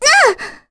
Kirze-Vox_Damage_kr_04.wav